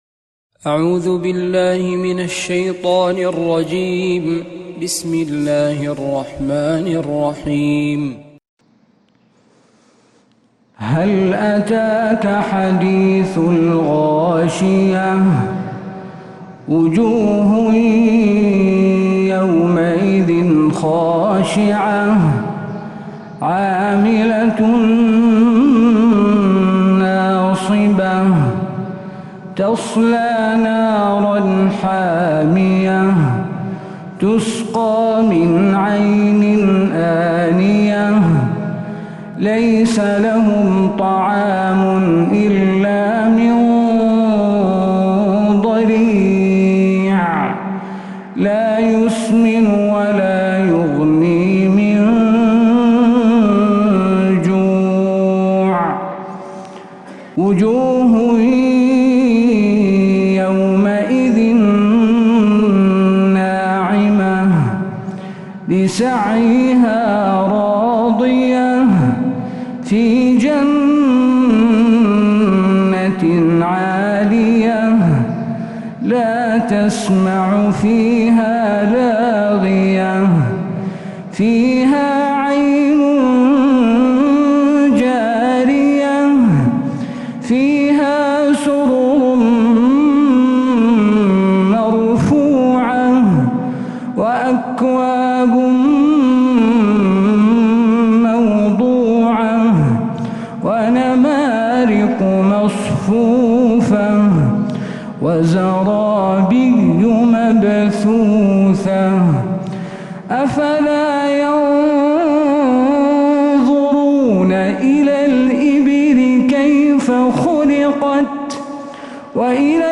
سورة الغاشية كاملة من فجريات الحرم النبوي